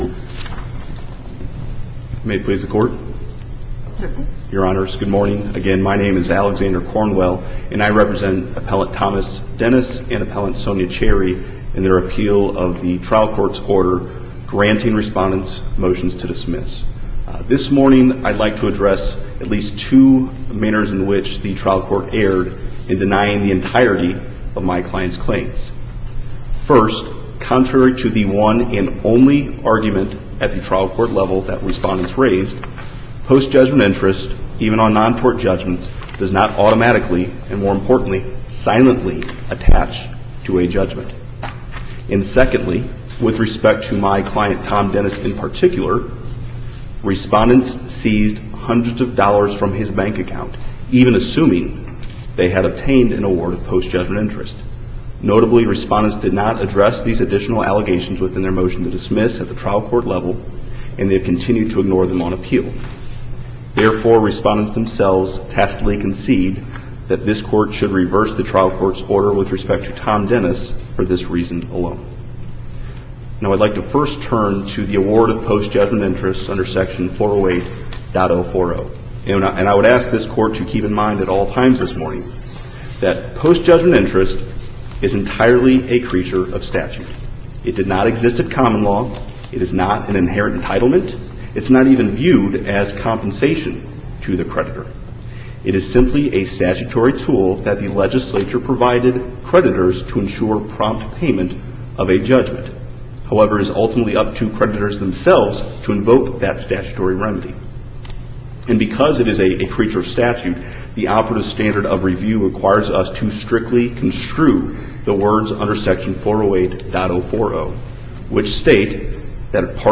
MP3 audio file of arguments in SC95976